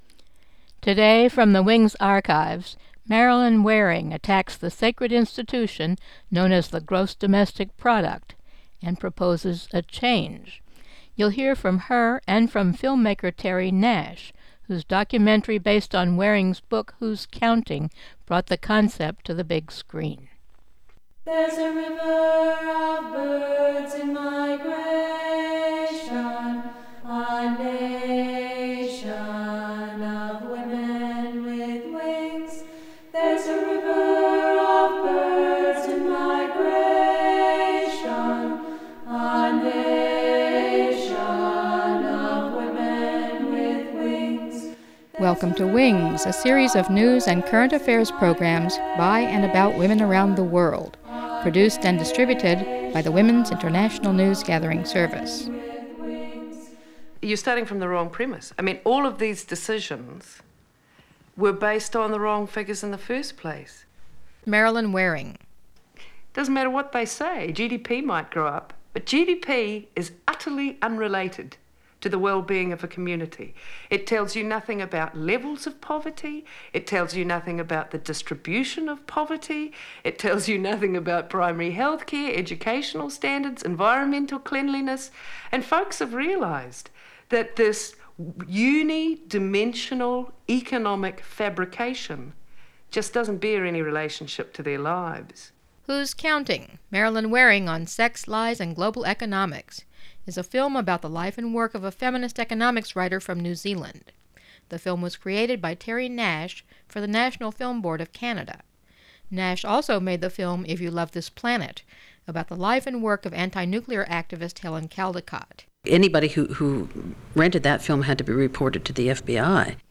Film "Who's Counting?" Excerpts